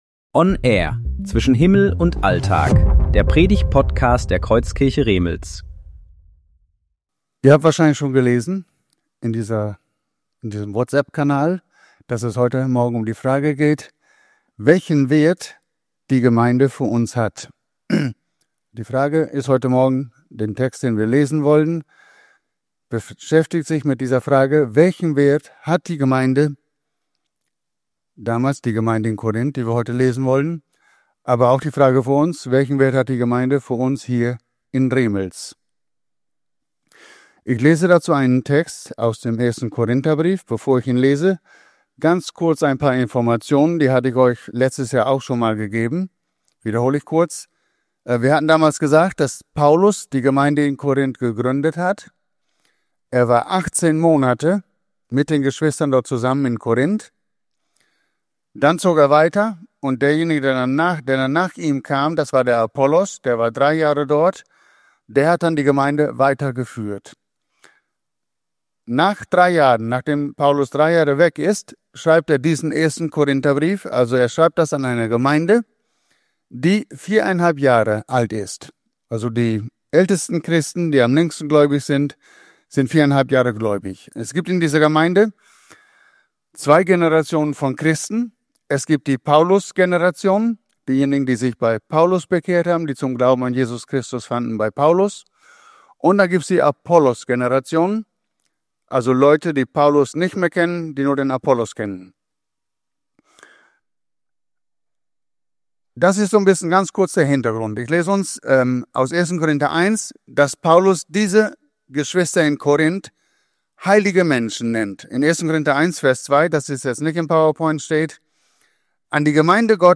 Predigtserie: Gottesdienst